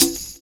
DRUM SECT 18.wav